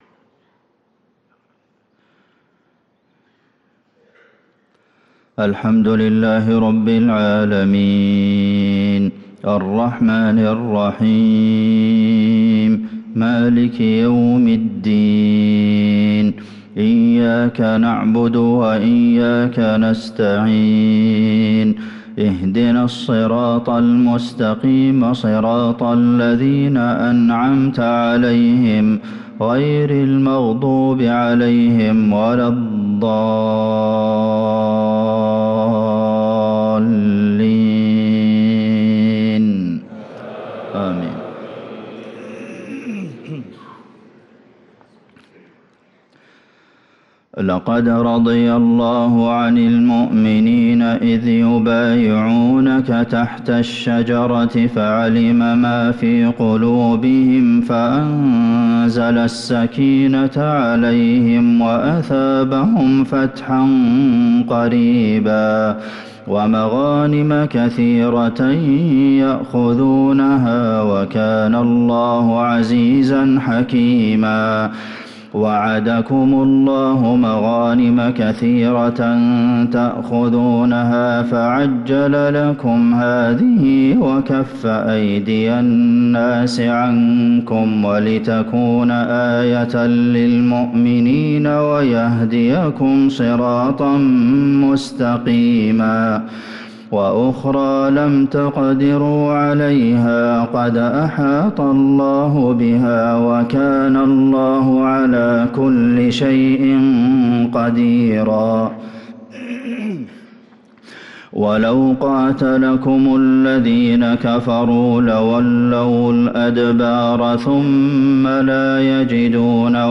صلاة الفجر للقارئ عبدالمحسن القاسم 19 جمادي الأول 1445 هـ
تِلَاوَات الْحَرَمَيْن .